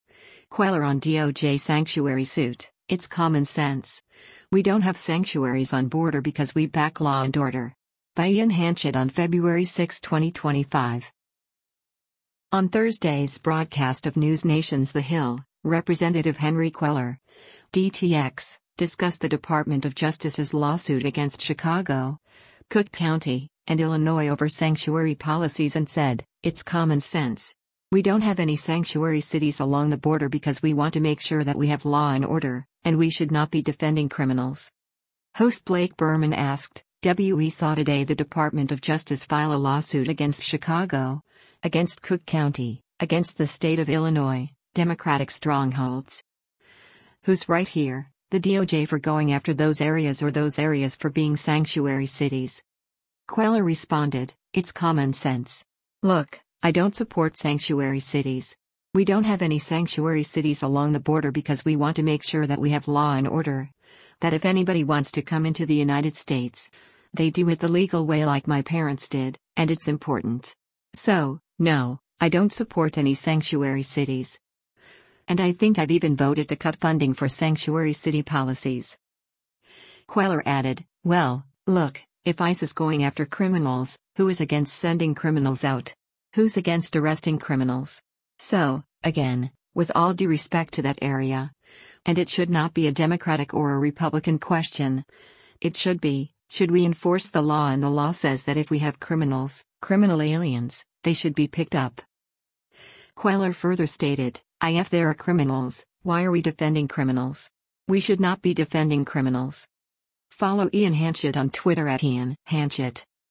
On Thursday’s broadcast of NewsNation’s “The Hill,” Rep. Henry Cuellar (D-TX) discussed the Department of Justice’s lawsuit against Chicago, Cook County, and Illinois over sanctuary policies and said, “It’s common sense.” “We don’t have any sanctuary cities along the border because we want to make sure that we have law and order,” and “We should not be defending criminals.”